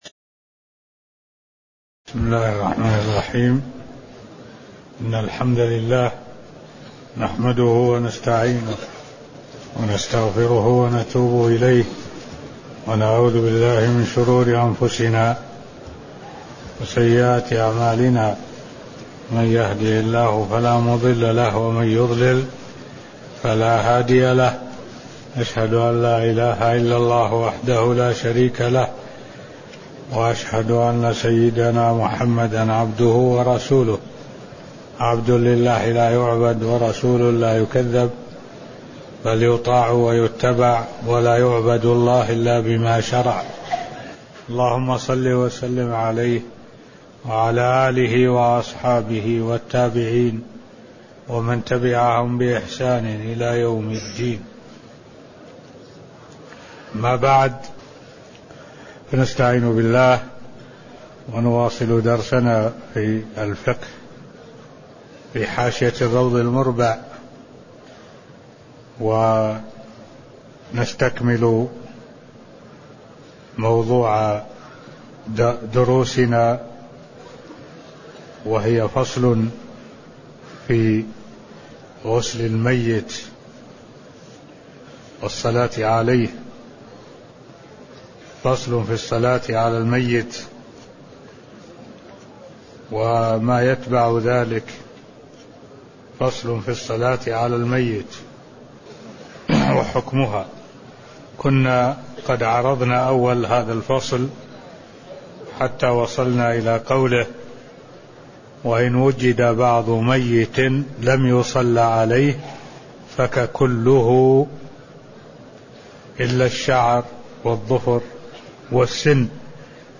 تاريخ النشر ١٨ ذو الحجة ١٤٢٦ هـ المكان: المسجد النبوي الشيخ: معالي الشيخ الدكتور صالح بن عبد الله العبود معالي الشيخ الدكتور صالح بن عبد الله العبود غسل الميت والصلاة عليه (004)